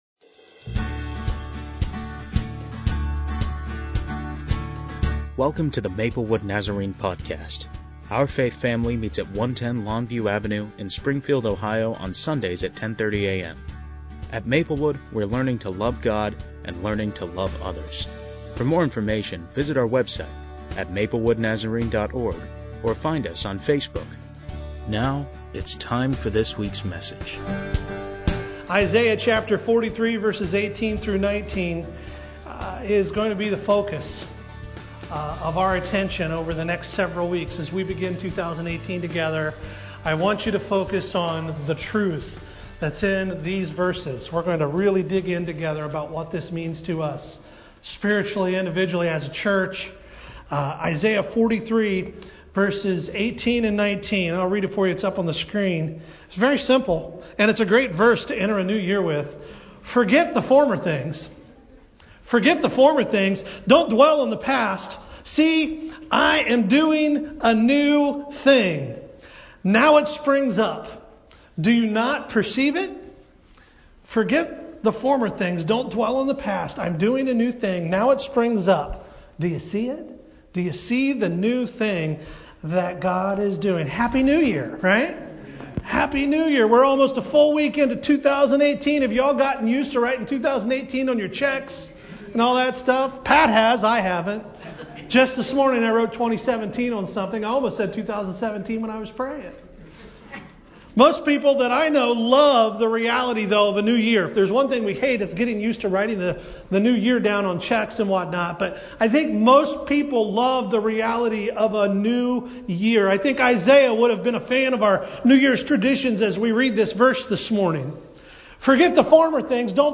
This week, Maplewood begins a new sermon series entitled "All Things New!"